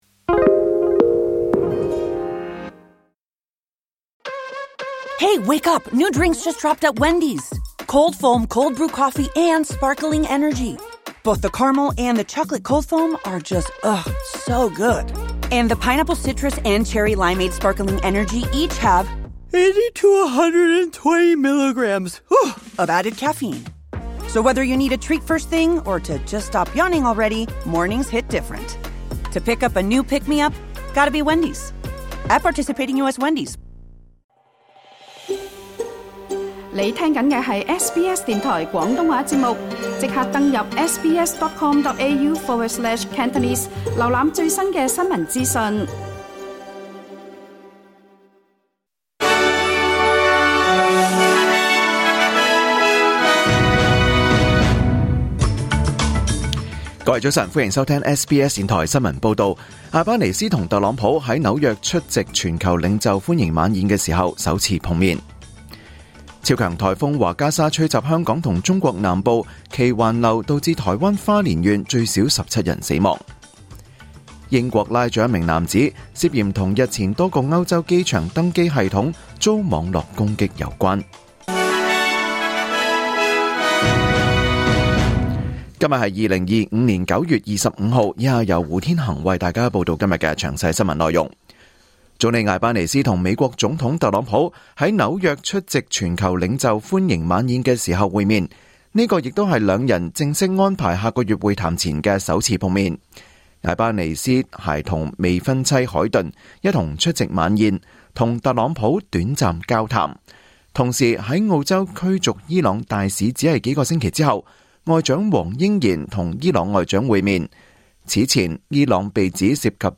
2025年9月25日 SBS 廣東話節目九點半新聞報道。